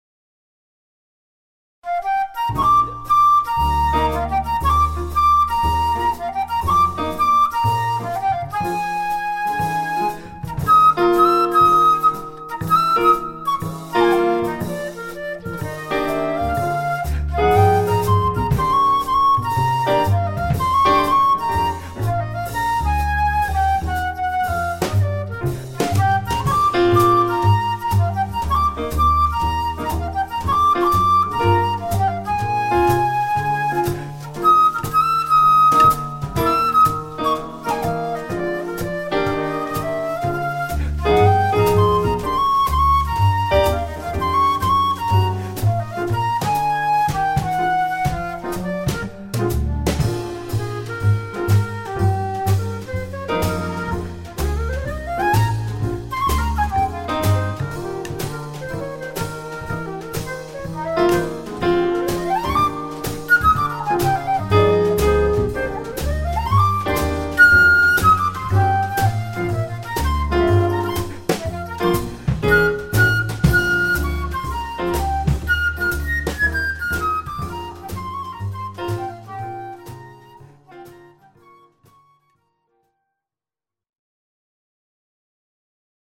quartet w/ flute